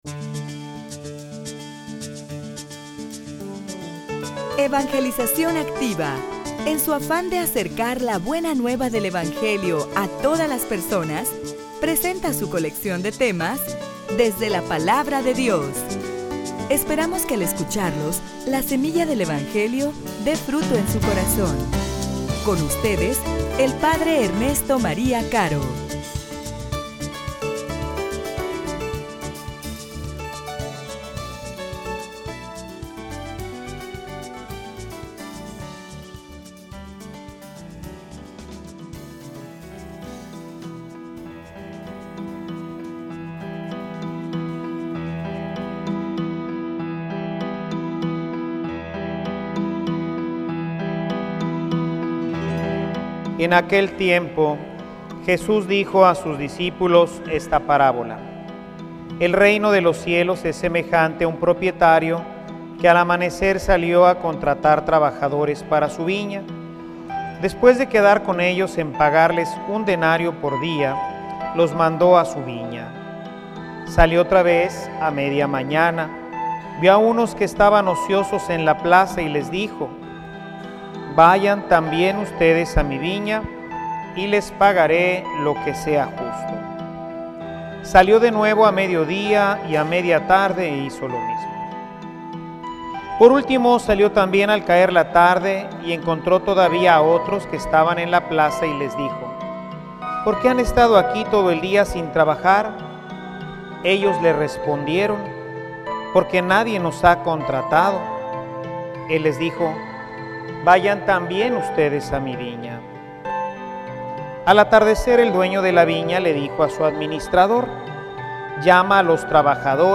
homilia_Date_tiempo_para_conocer_a_Dios.mp3